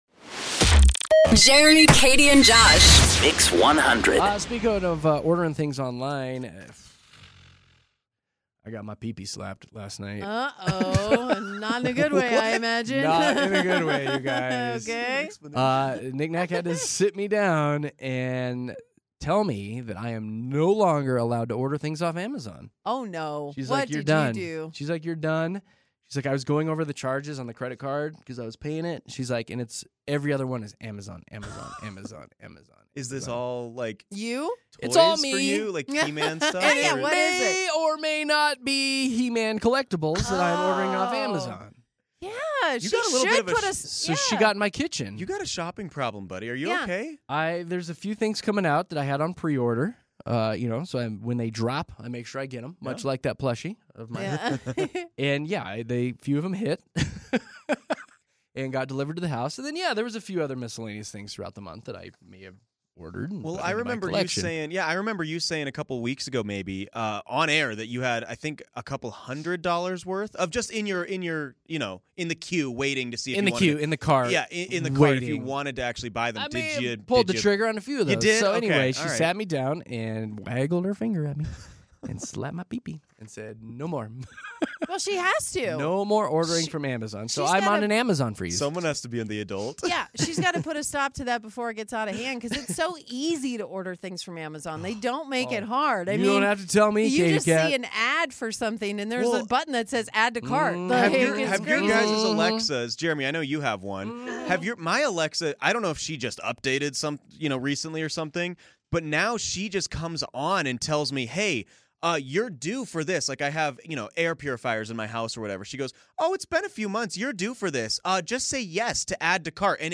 Denver's favorite morning radio show!